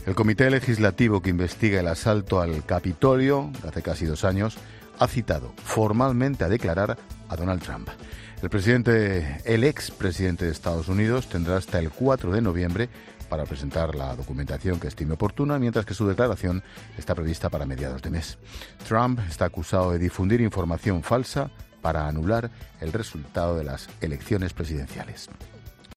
Ángel Expósito cuenta en 'La Linterna' la citación a declarar al ex presidente americano Donald Trump